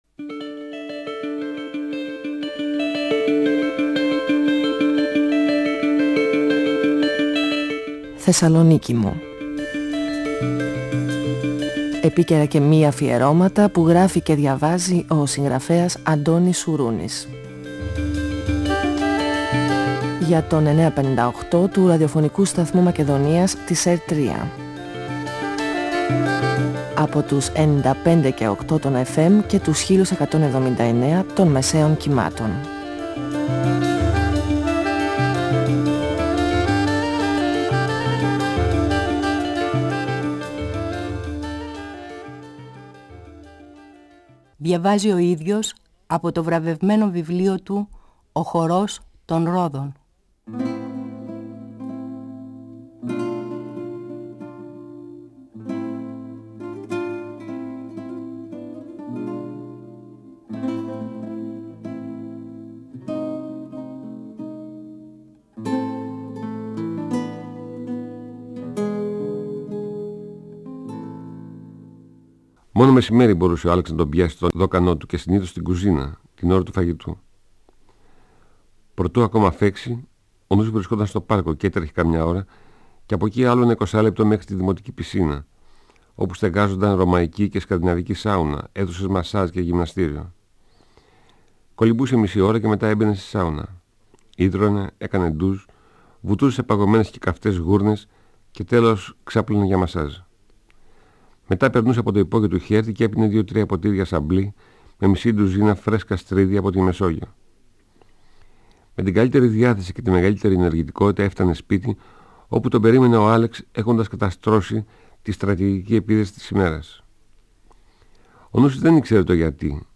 Ο συγγραφέας Αντώνης Σουρούνης (1942-2016) διαβάζει από το βιβλίο του «Ο χορός των ρόδων», εκδ. Καστανιώτη, 1994. Ο Νούσης δυσκολεύεται να προσαρμοστεί στις νέες συνθήκες ζωής, με την Ιρίνα και τον πατέρας της τον Άλεξ.